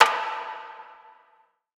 Metro Ambient Perc 3.wav